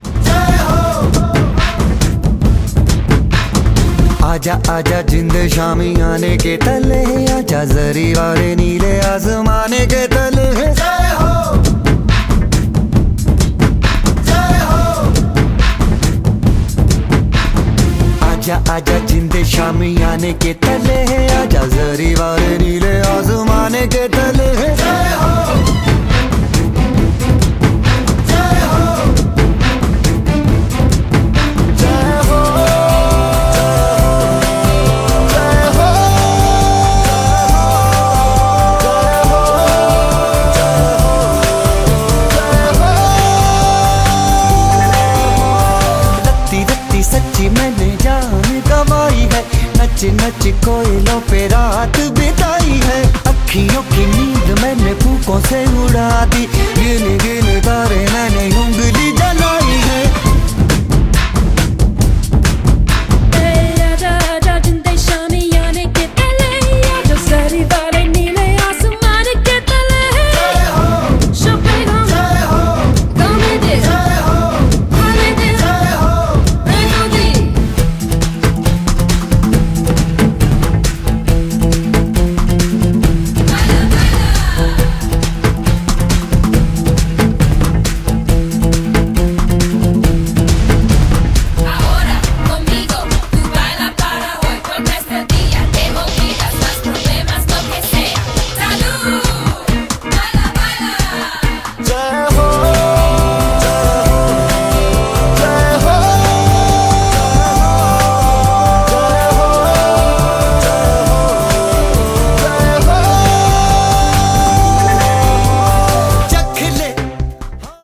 BPM137
Audio QualityPerfect (High Quality)
Either way, the sound quality is great!